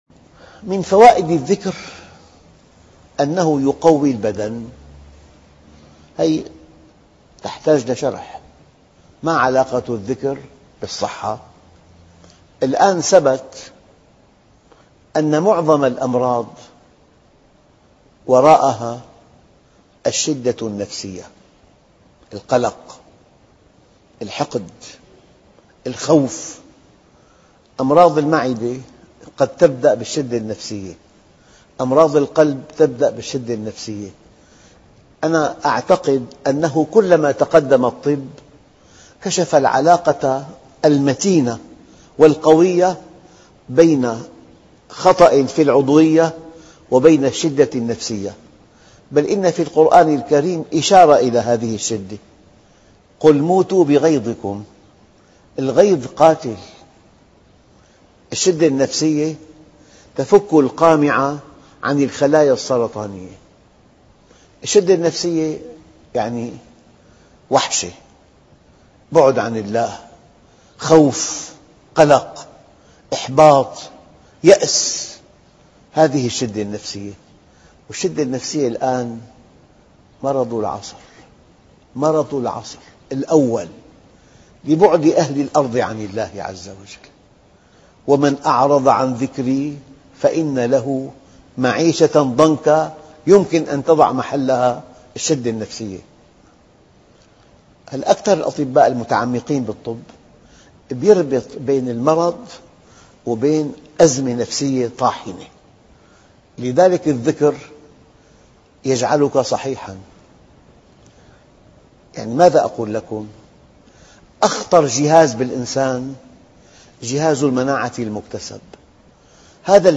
ماهي علاقة ذكر الله تعالى بالشدّة النفسيّة ؟؟؟ .. درس هااام ...